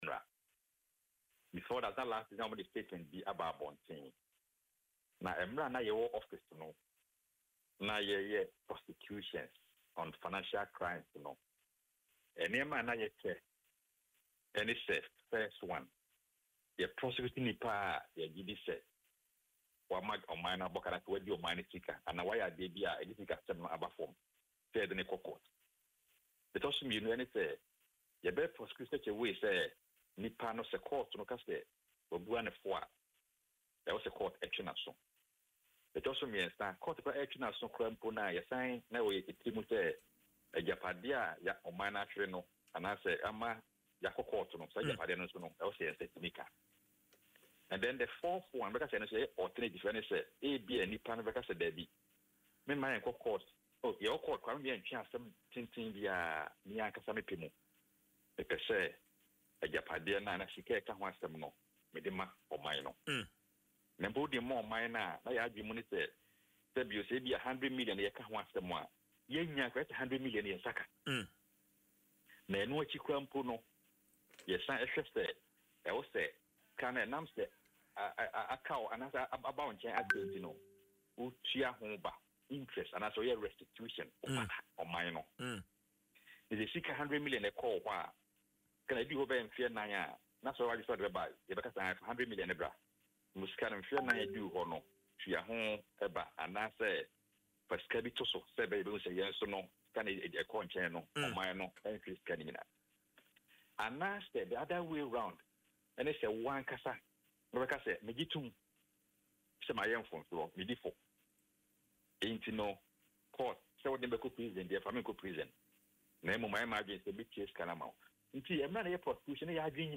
Speaking in an interview on Adom FM’s Dwaso Nsem, Mr. Yeboah insisted that the case was progressing steadily with a strong commitment to recovering lost funds.